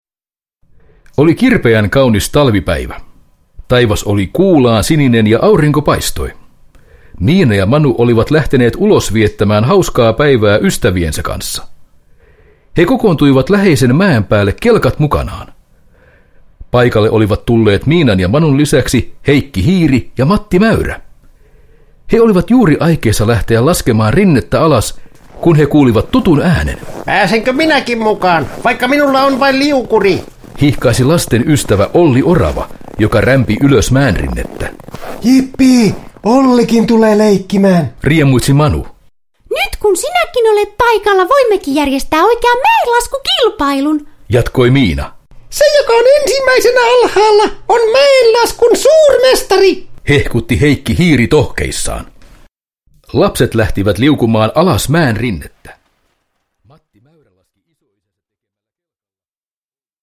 Miina ja Manu Talvirieha – Ljudbok – Laddas ner